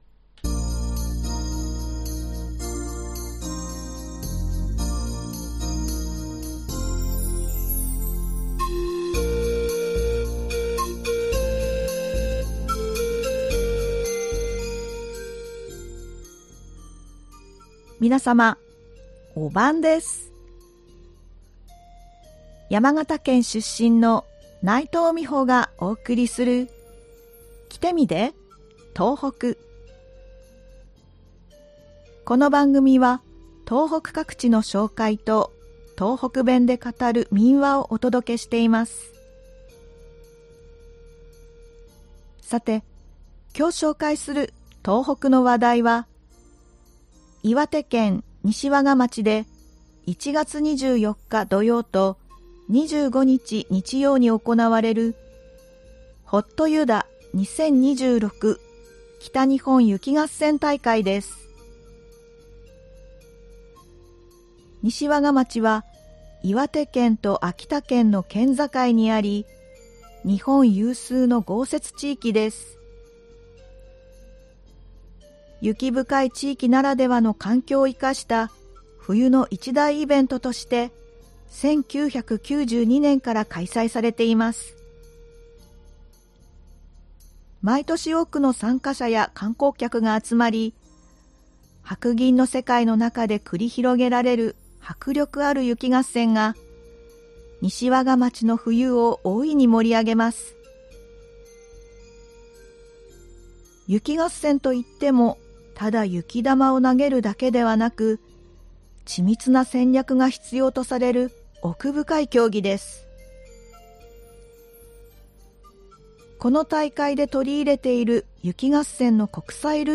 この番組は東北各地の紹介と、東北弁で語る民話をお届けしています。
ではここから、東北弁で語る民話をお送りします。今回は岩手県で語られていた民話「神さんのかっちき」をお送りします。